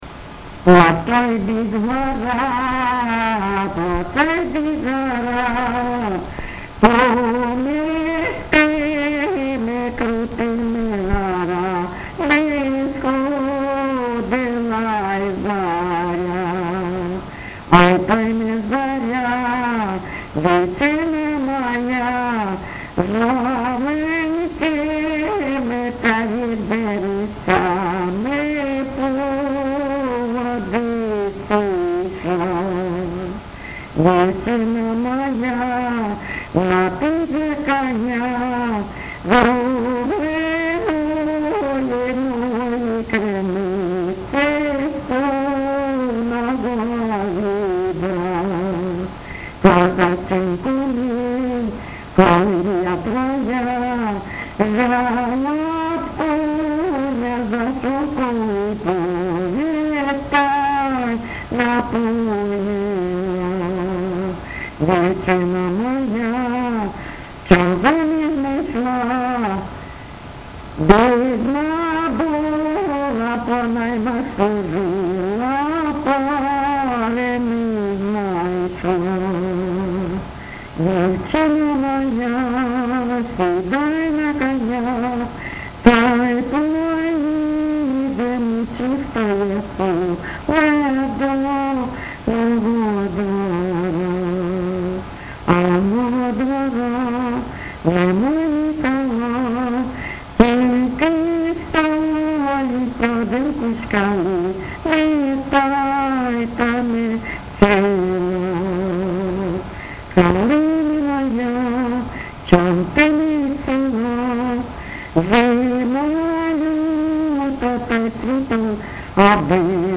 ЖанрПісні з особистого та родинного життя